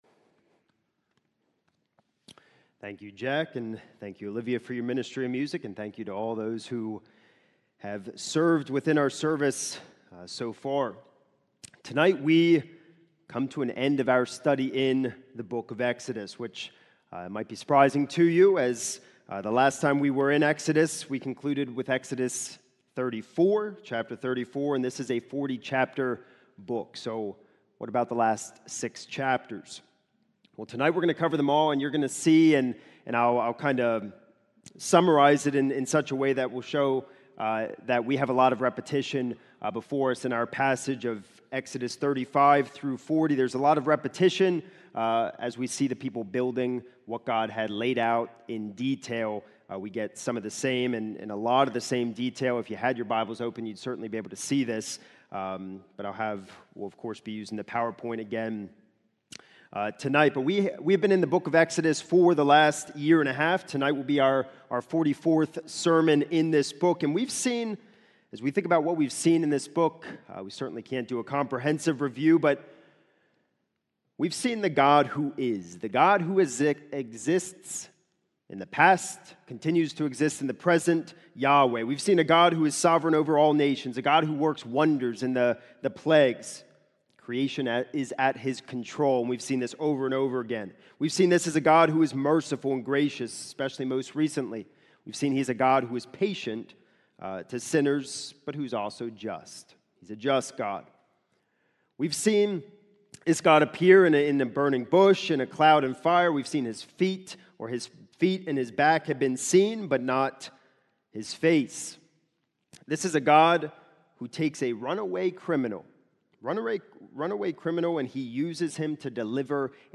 This is a sermon recorded at the Lebanon Bible Fellowship Church in Lebanon, PA during the evening worship service on 8/3/2025